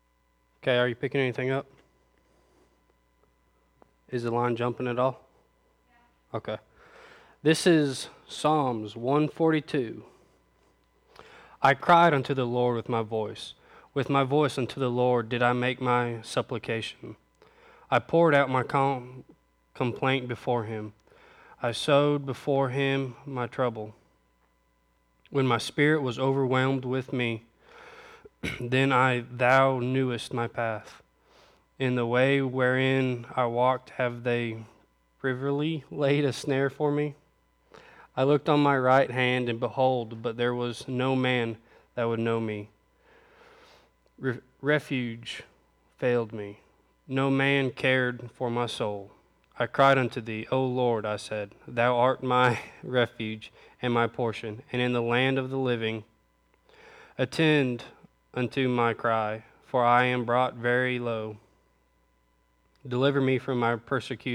A Scripture Reading